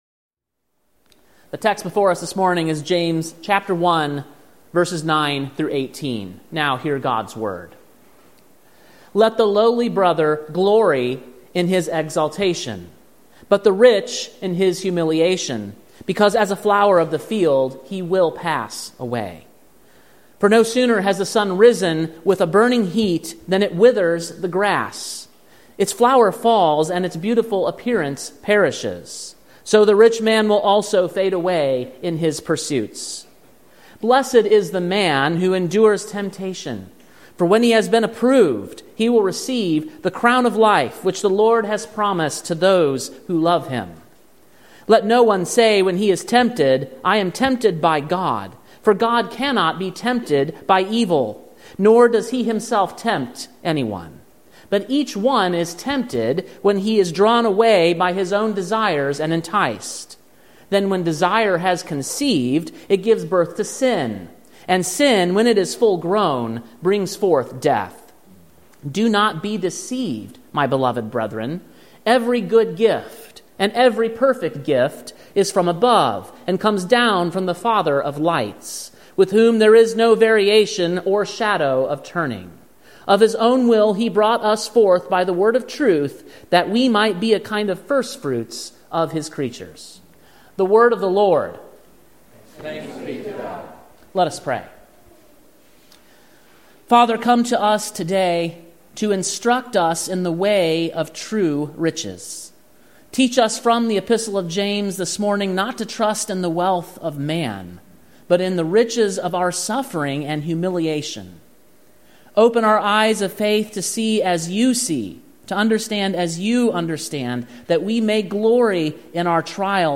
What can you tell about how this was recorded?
Sermon preached on June 23, 2024, at King’s Cross Reformed, Columbia, TN.